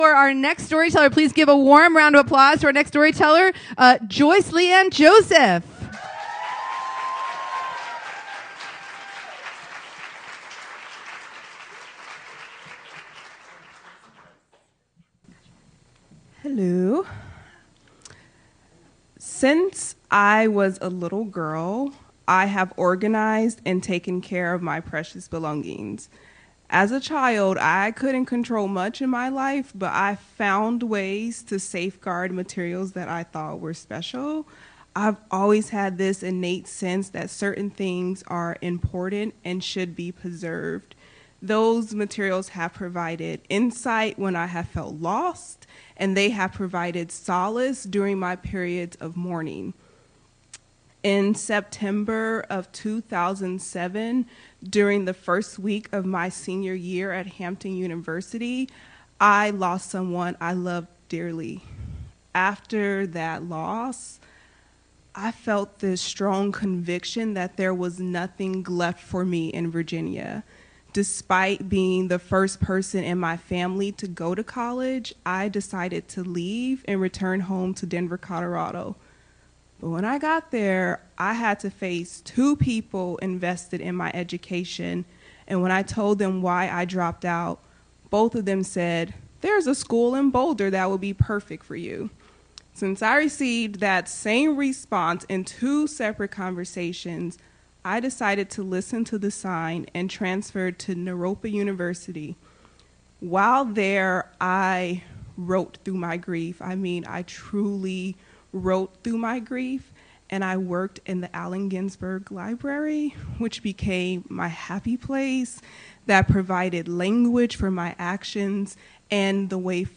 “A Finding Aid to My Soul” A Storytelling Event Celebrating Archivists on October 1.